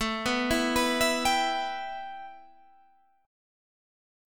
A7sus2 chord